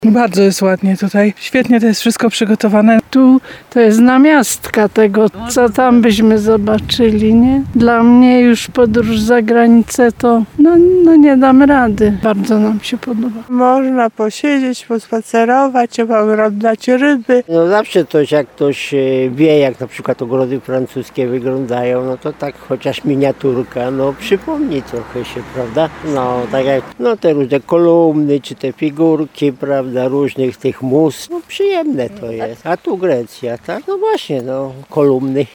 23sonda_muszyna.mp3